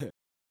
KIN Huh.wav